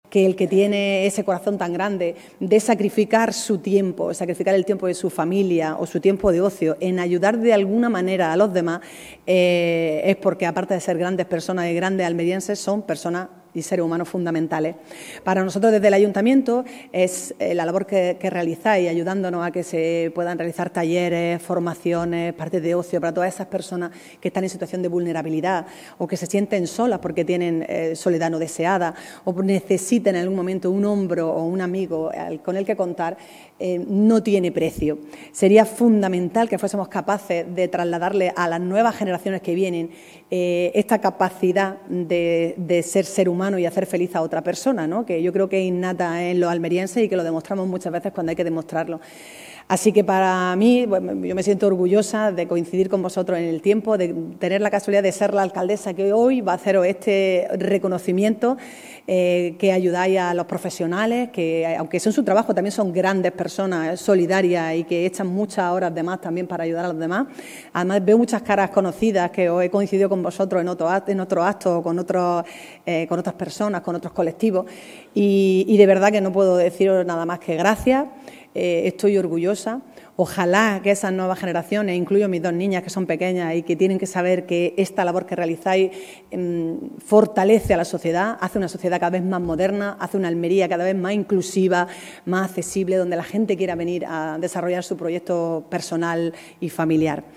La alcaldesa preside el acto institucional en el Salón de Plenos en el que se ha agradecido “la extraordinaria labor” del más de centenar de Voluntarios de los centros de Servicios Sociales Comunitarios
ALCALDESA-VOLUNTARIADO.mp3